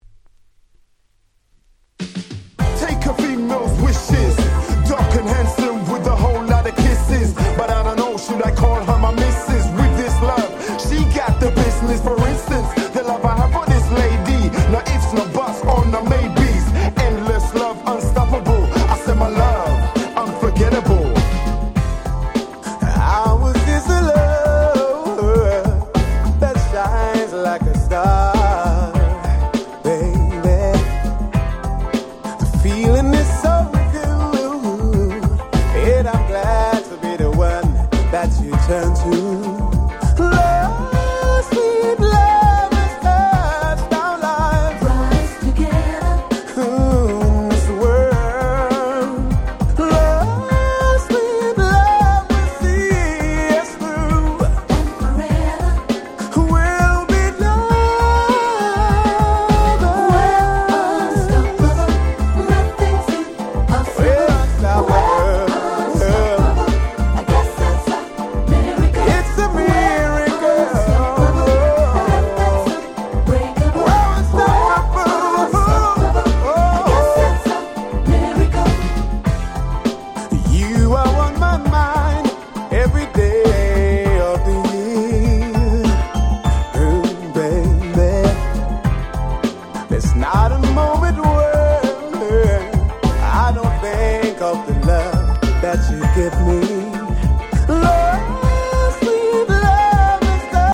Super Nice UK R&B !!